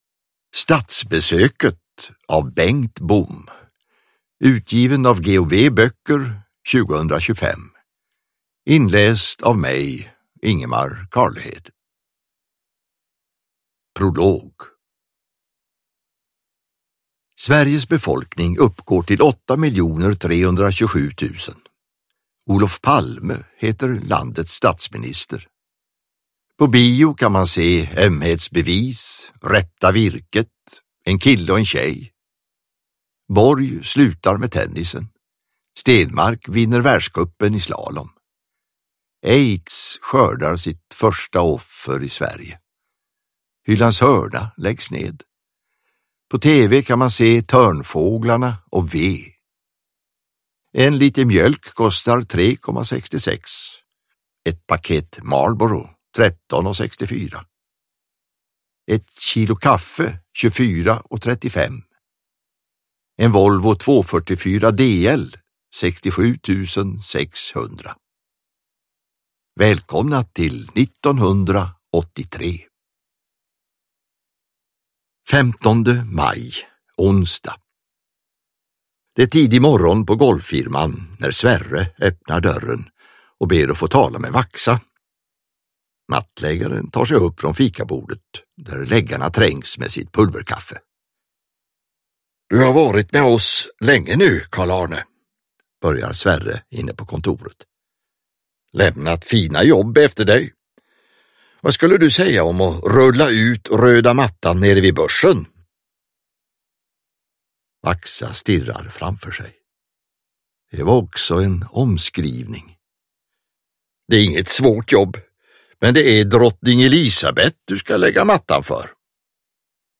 Statsbesöket – Ljudbok